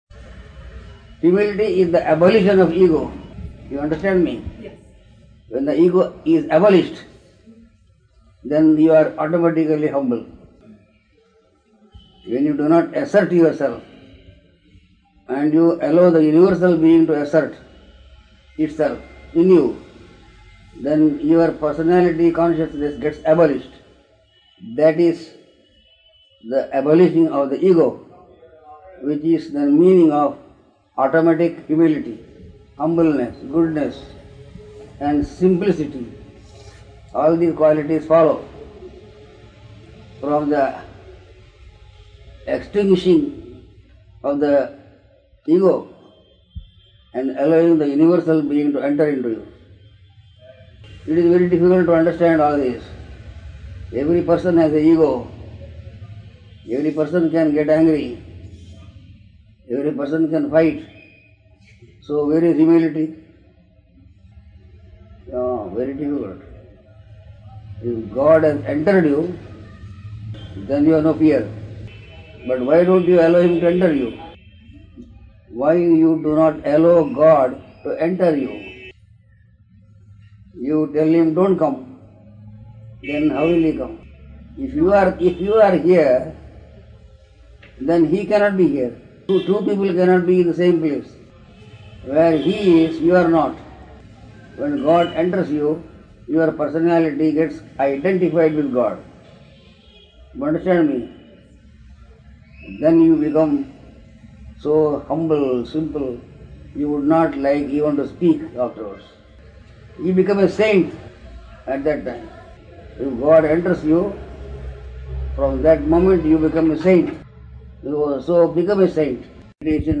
Humility, Meditation - Darshan of Swami Krishnananda in 1998
(Darshan given in March 1998)